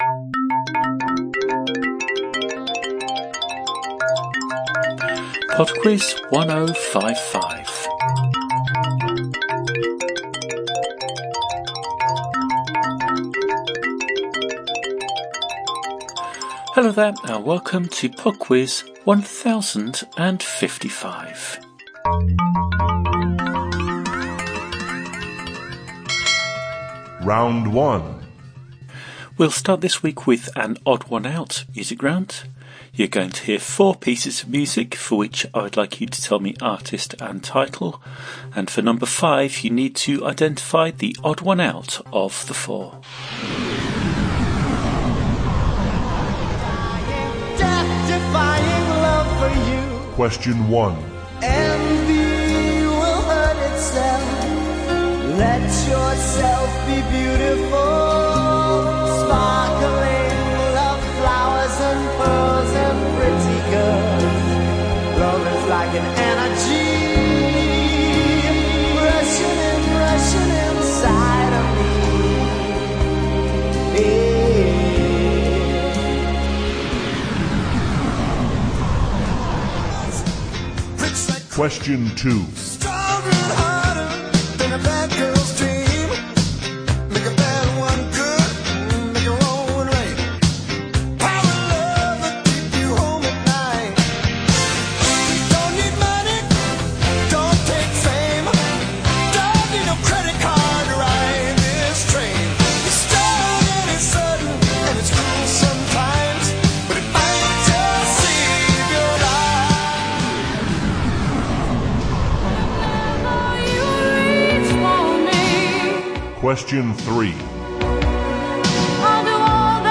A weekly twenty question trivia quiz podcast.